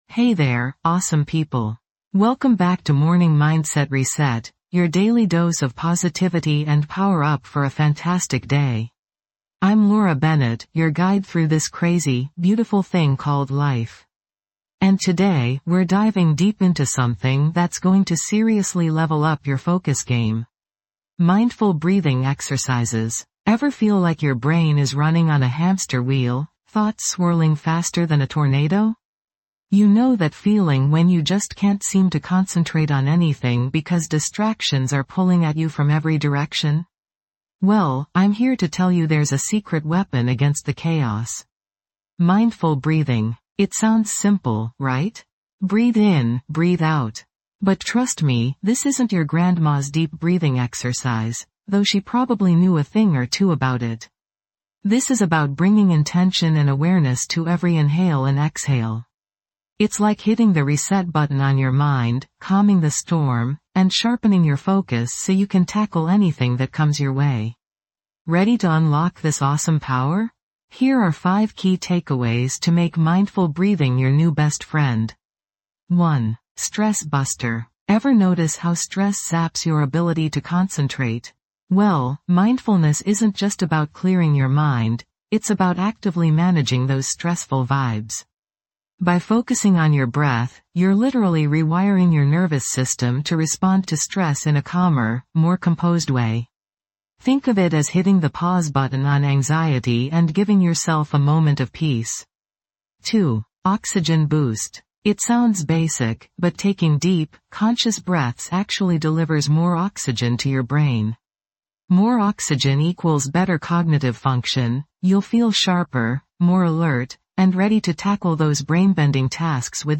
Morning Mindset Reset | Anxiety-Safe Daily Start delivers gentle guidance and mindfulness exercises designed to ease anxiety and create a peaceful foundation for your day. Each episode offers practical tools and affirmations to help you manage stress, cultivate inner peace, and approach your day with a sense of calm confidence.